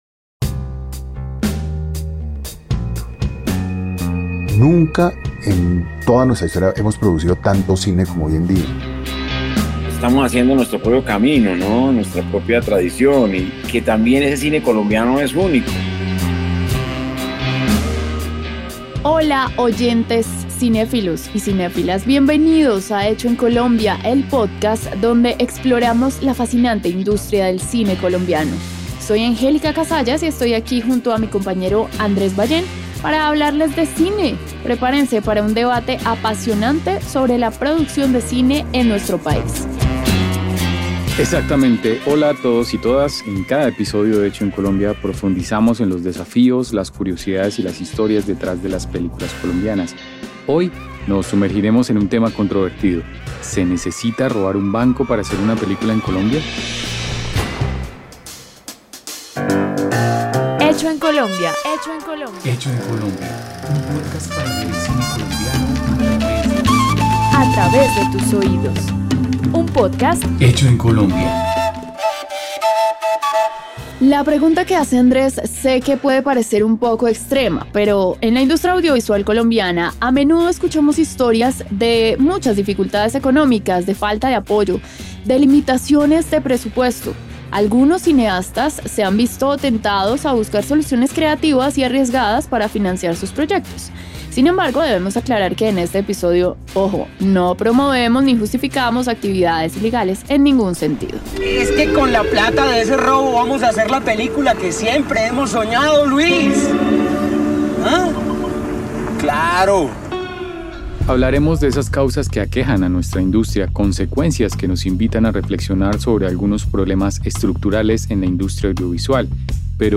¿Es necesario robar un banco para hacer una película? Un debate sobre los desafíos de producir cine en Colombia: desde los elementos que afectan a la industria hasta los problemas estructurales en la realización, distribución y exhibición.